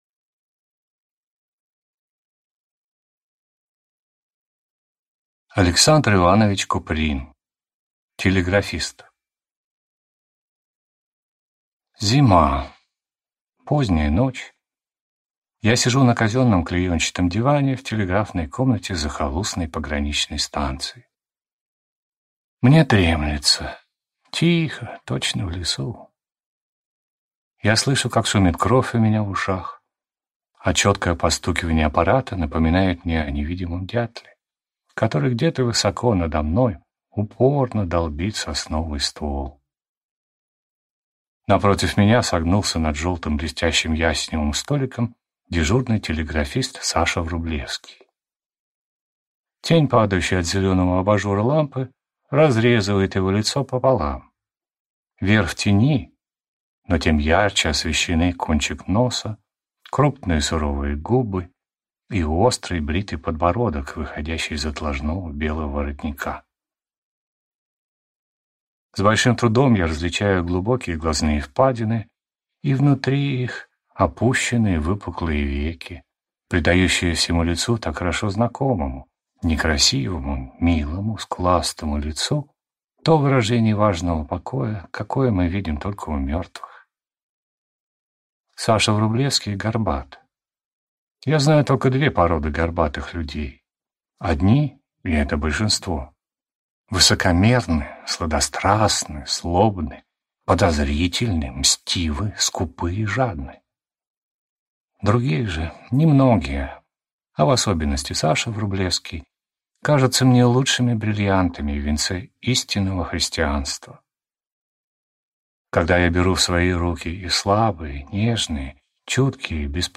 Аудиокнига Телеграфист | Библиотека аудиокниг